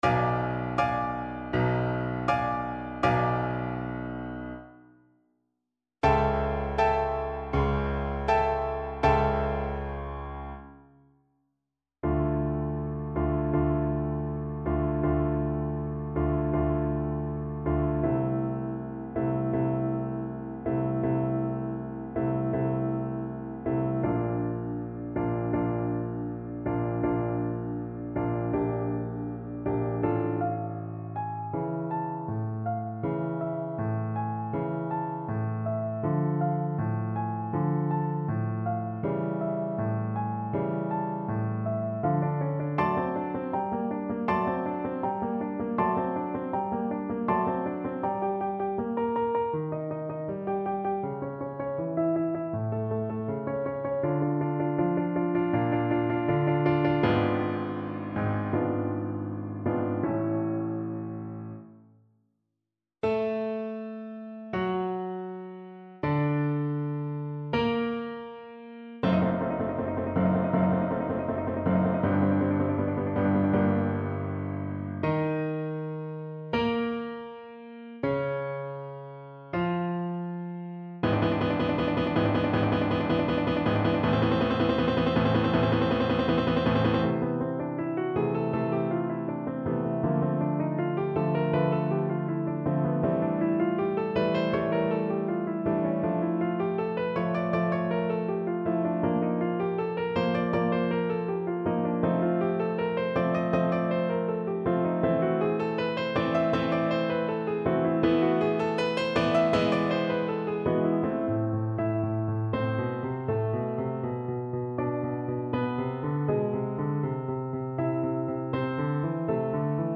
Play (or use space bar on your keyboard) Pause Music Playalong - Piano Accompaniment Playalong Band Accompaniment not yet available transpose reset tempo print settings full screen
Trombone
D minor (Sounding Pitch) (View more D minor Music for Trombone )
Andante
4/4 (View more 4/4 Music)
Classical (View more Classical Trombone Music)
Dramatic & Epic music for Trombone